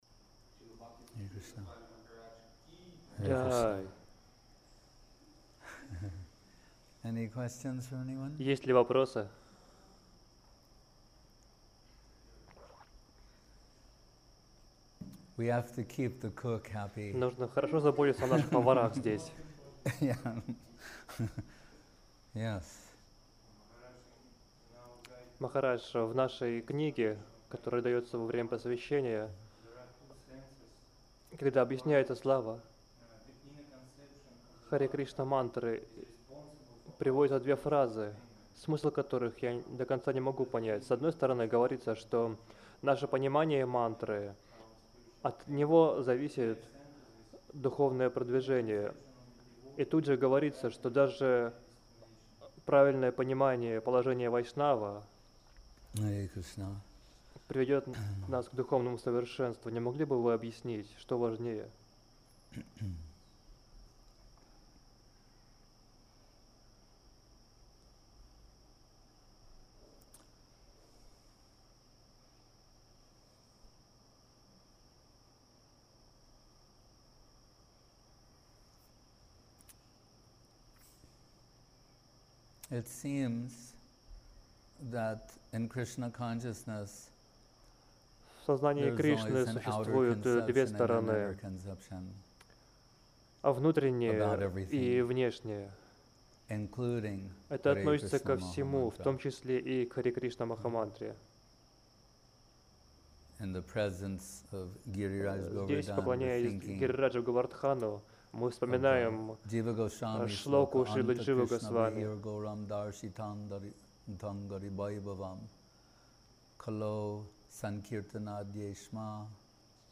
Место: Гупта Говардхан Чианг Май